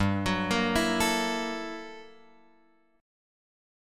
GmM13 chord {3 x 4 3 5 5} chord